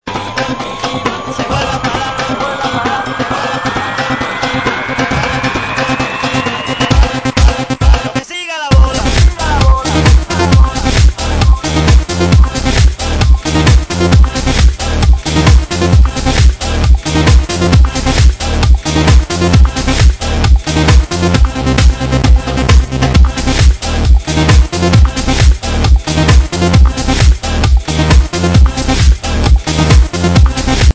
ID this really funky house song....